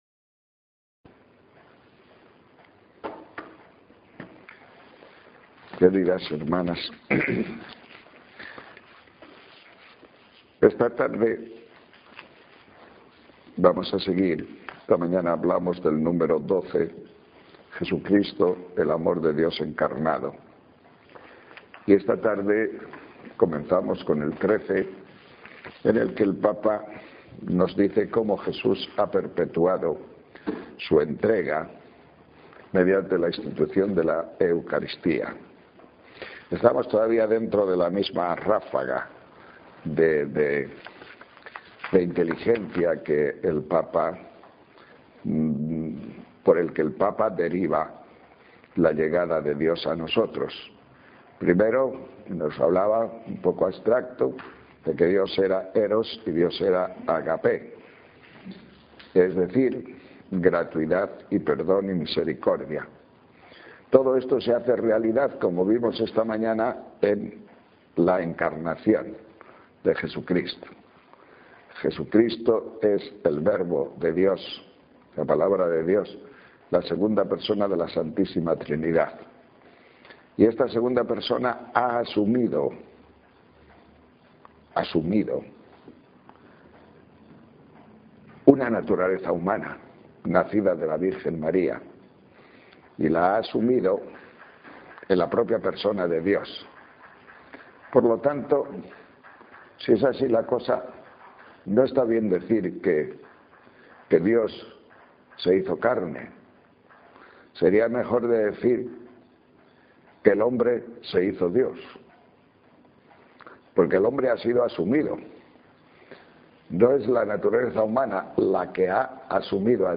Ejercicios espirituales sobre la Enc�clica "Deus caritas est" Marzo 2006 a las monjas dominicas de X�tiva